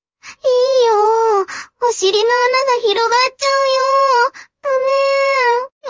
Canary-TTS-0.5Bをつまんでみたで
プロンプトで声を指定できるんでヤンデレとツンデレの声をChatGPTに説明してもろて生成してもろた
CanaryTts_1_Yandere.mp3